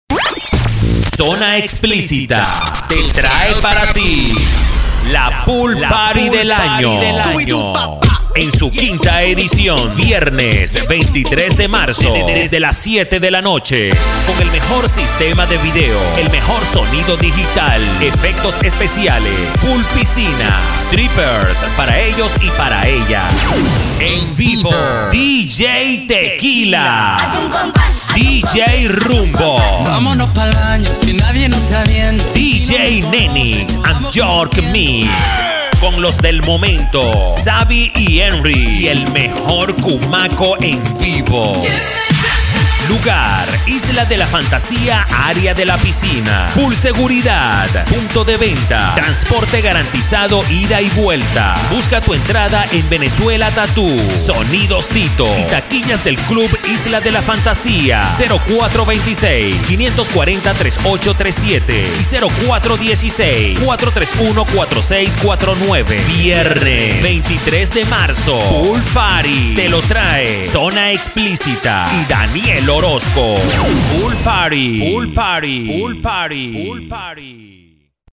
kolumbianisch
Sprechprobe: Sonstiges (Muttersprache):